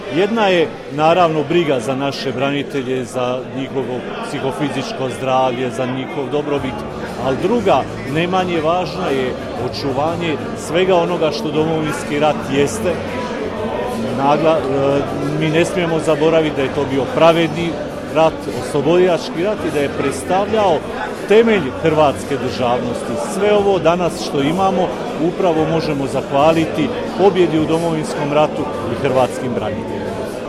U srijedu, 15. ožujka 2023. godine, u Sisku u Hotelu Panonija svečano je obilježena 32. obljetnica osnutka Specijalne jedinice policije „OSA“.